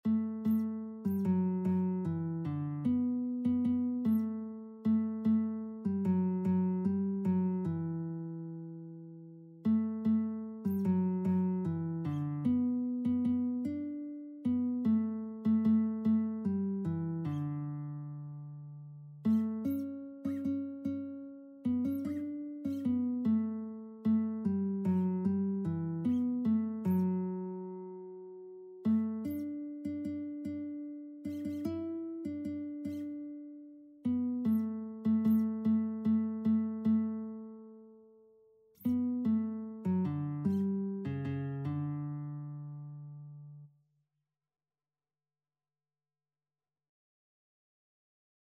Christian
6/8 (View more 6/8 Music)
Classical (View more Classical Lead Sheets Music)